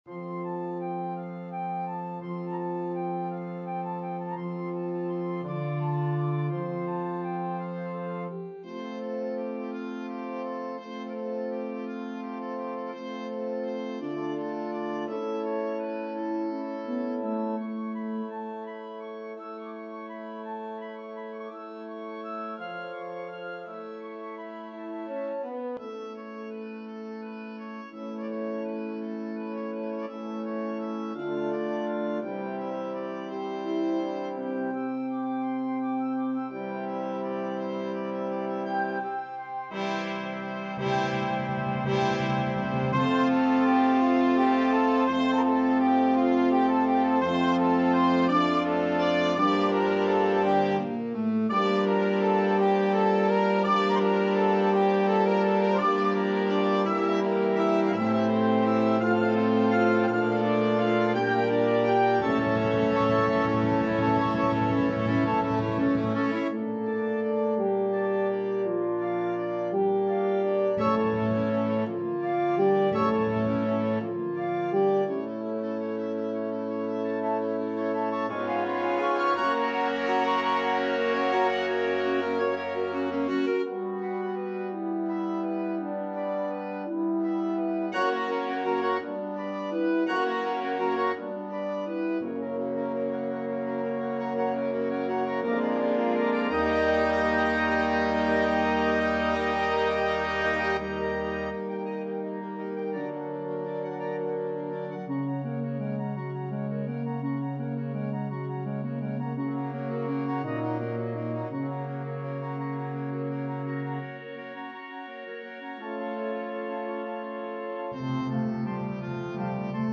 Allegretto pastorale
Andante doloroso
Tempo di Mazurka
Alla marcia e molto marcato